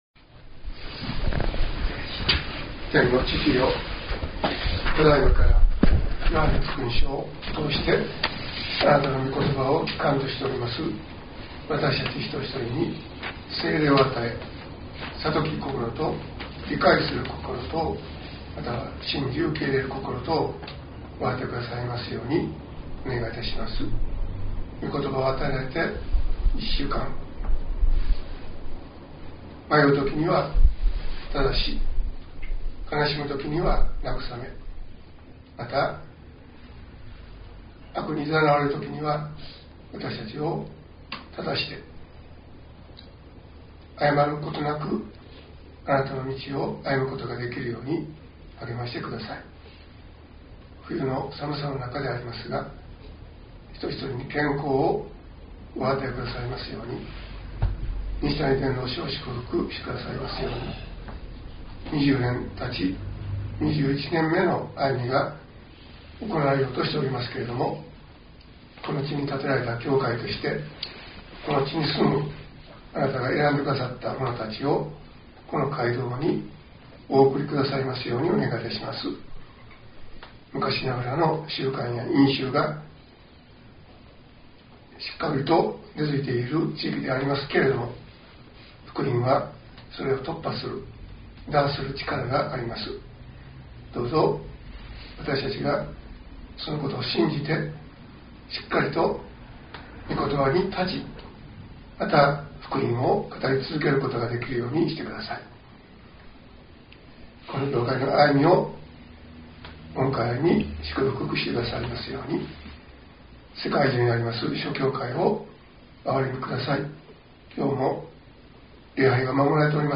2018年1月21日説教「救い主イエス」